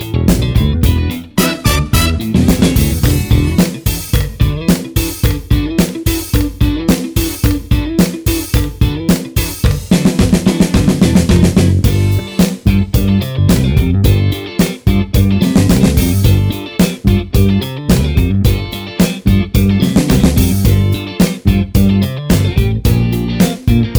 no Backing Vocals Disco 5:00 Buy £1.50